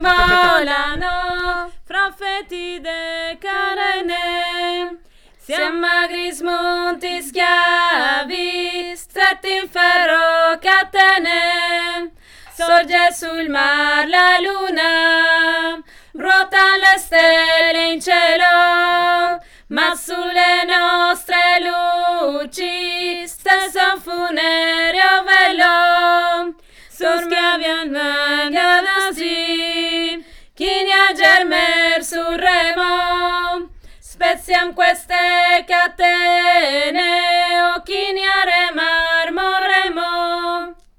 Il_galeone_3eme_voix.mp3